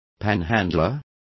Complete with pronunciation of the translation of panhandler.